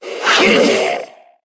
troll_mage_attack.wav